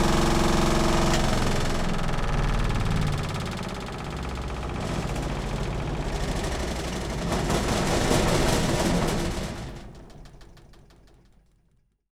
DRIER 2   -S.WAV